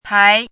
chinese-voice - 汉字语音库
pai2.mp3